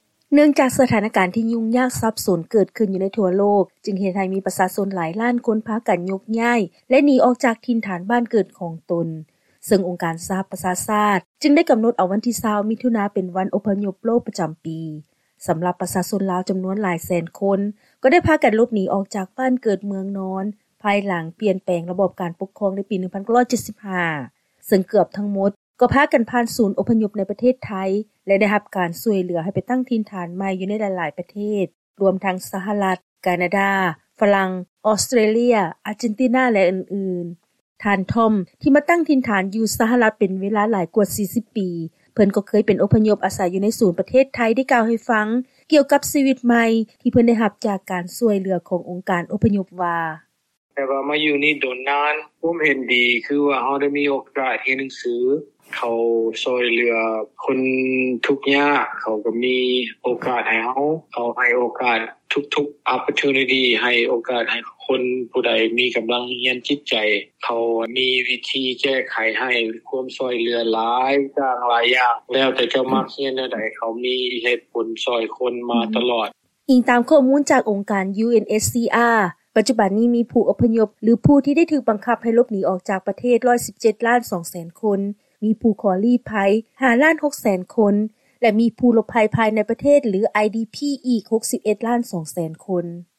ເຊີນຟັງຂ່າວກ່ຽວກັບ ຜູ້ອົບພະຍົບລາວ ໃນວັນອົບພະຍົບໂລກ